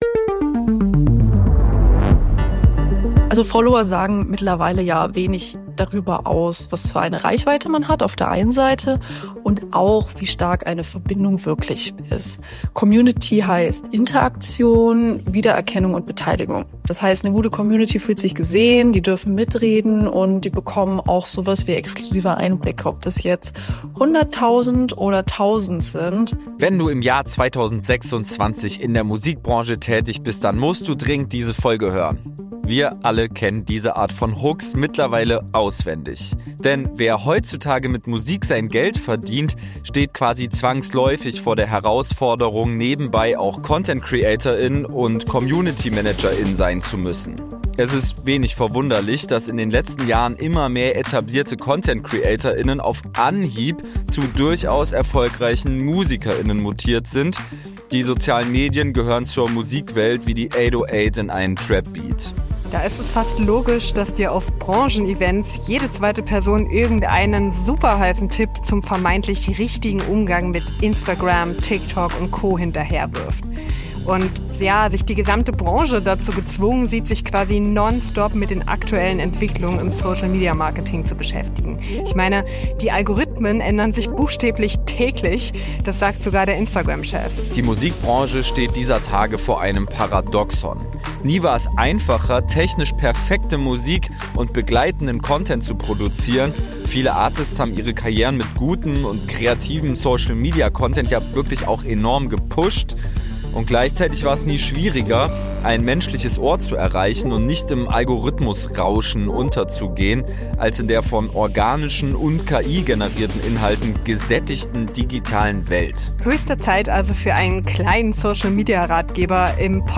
Im Laufe dieser Episode geben euch unsere Hosts ganz konkrete Tipps zum Umgang mit den sozialen Medien in 2026.